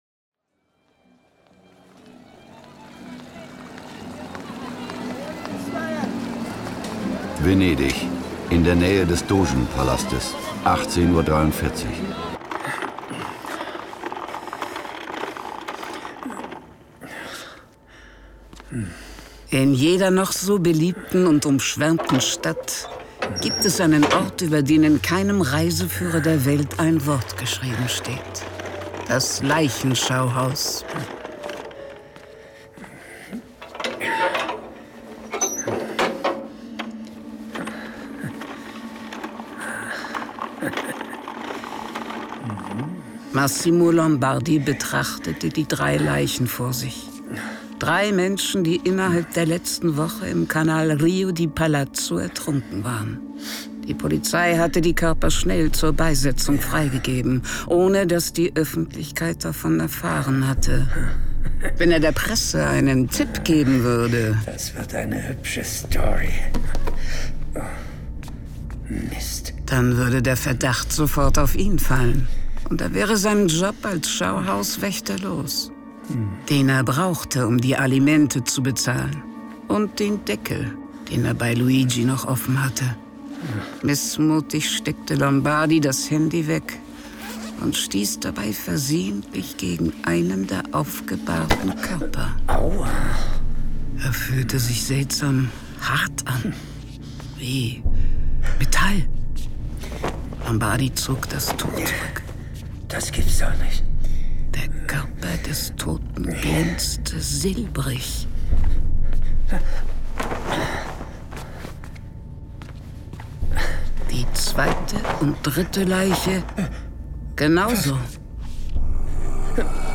John Sinclair - Folge 166 Der Doge, sein Henker und ich. Hörspiel.